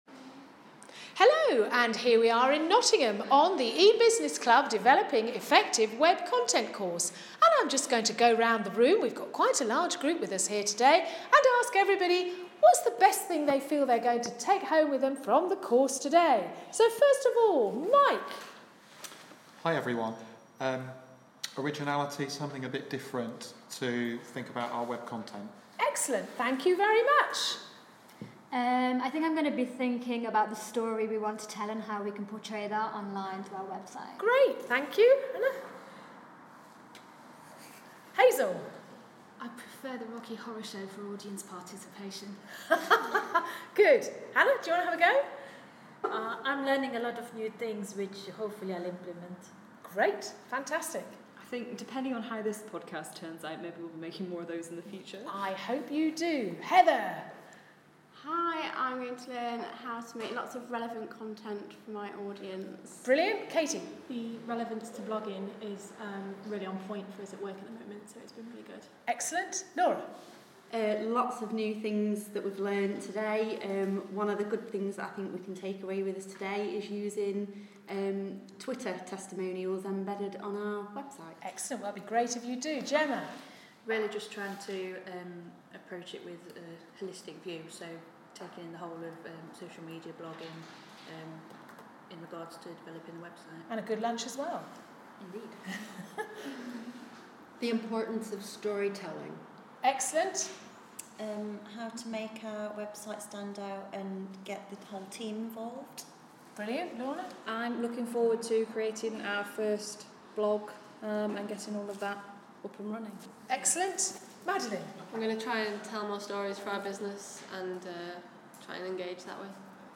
interviewing students on EBusinessClub course 'How to develop effective web content'